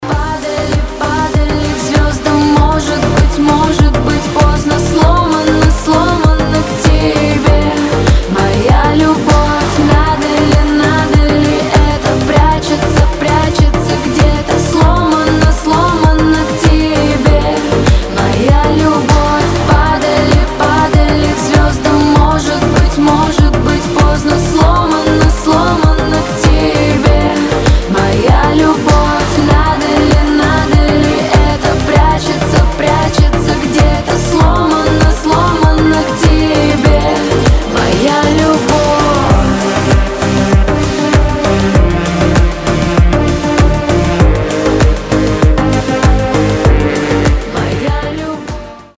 Женский голос
Поп